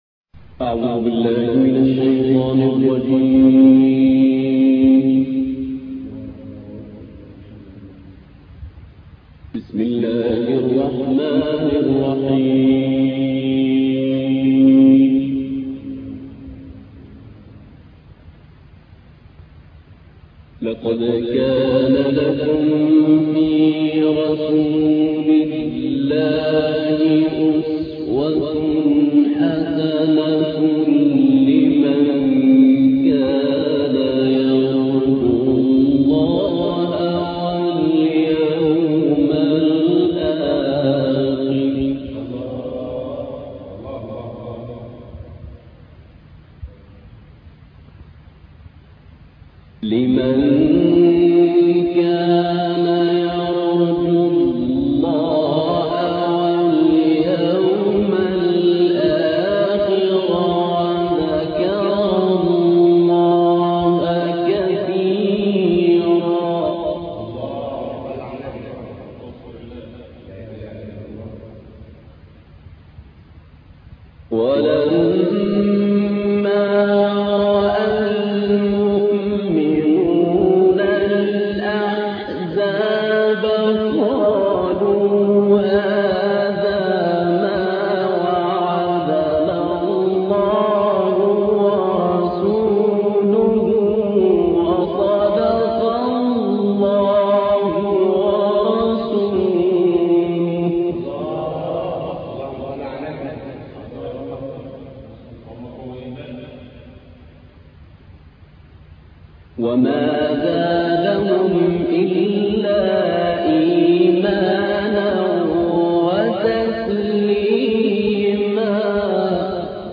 موقع نور القرأن | القارئ محمود أبو الوفا الصعيدي